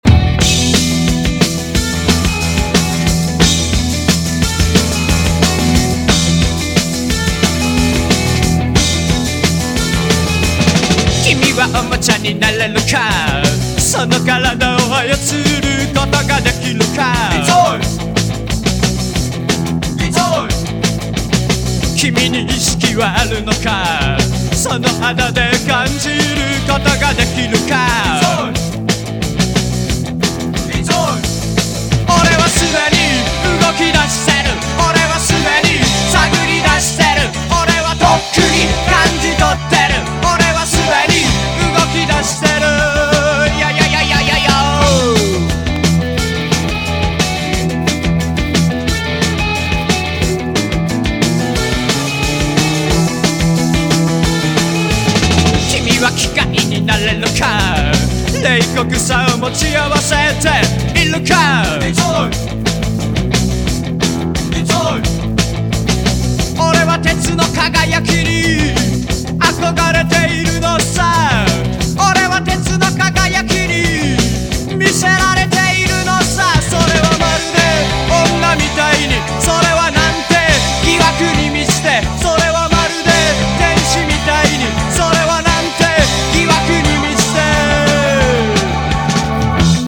ROCK / PUNK / 80'S～ / JAPANESE PUNK